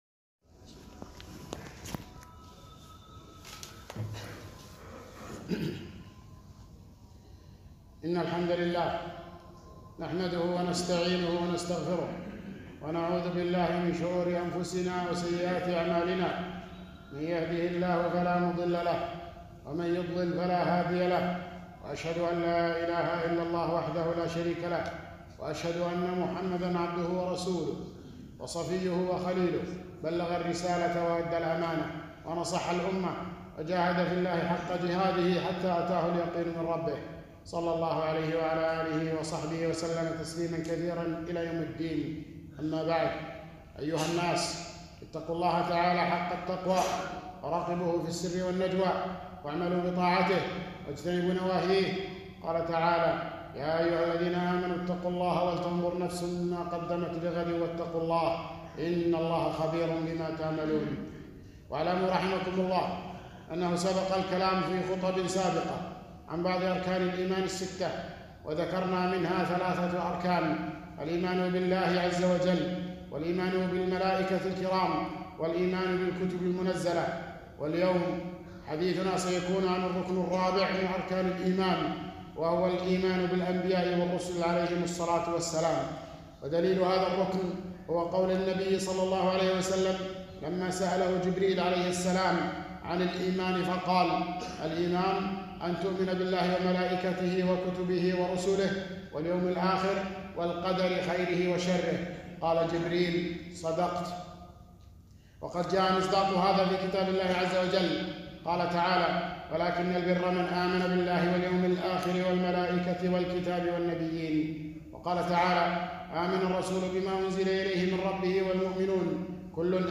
4- خطبة - الإيمان بالرسل